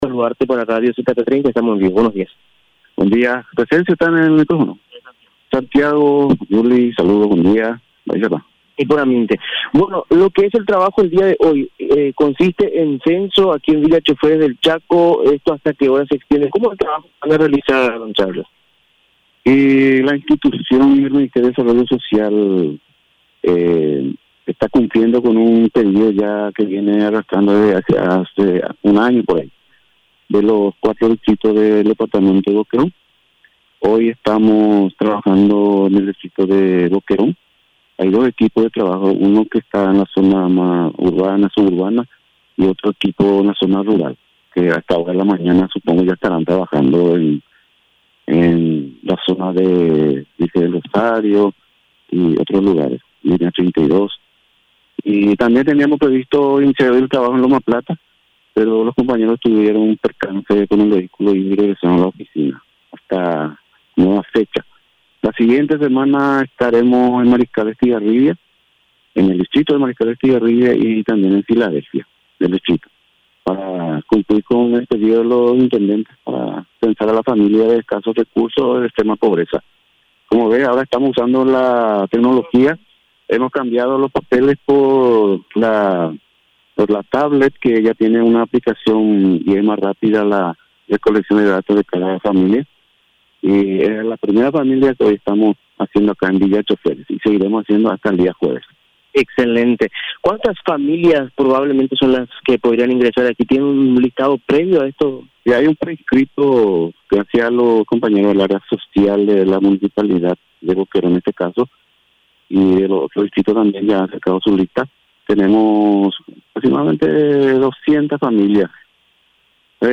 Entrevistas / Matinal 610
Estudio Central, Filadelfia, Dep. Boquerón